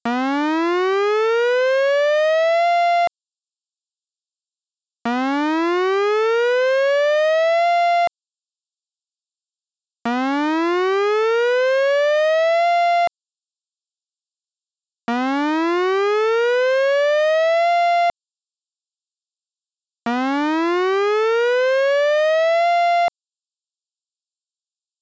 サイレン（3秒吹鳴2秒休止×5回）
(注意)試聴の際は、音量に御注意ください